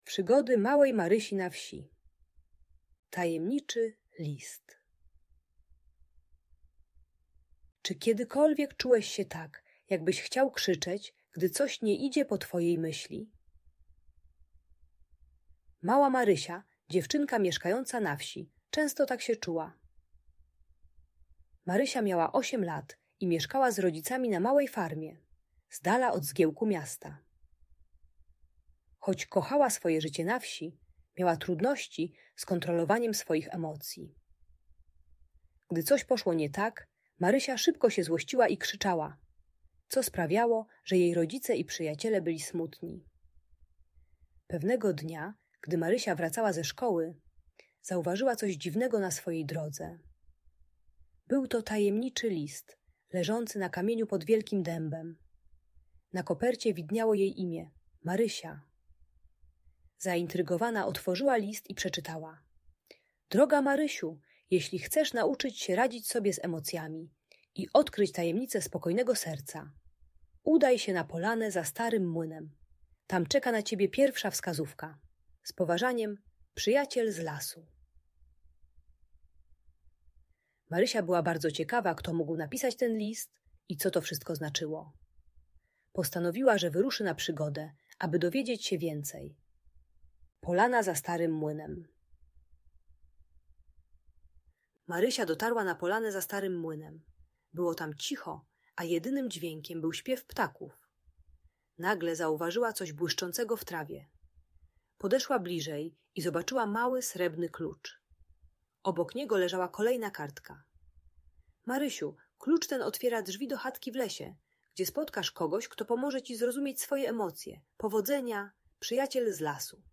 Przygody Małej Marysi na Wsi - Audiobajka